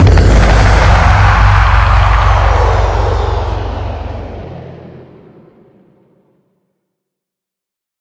minecraft / sounds / mob / wither / death.ogg
death.ogg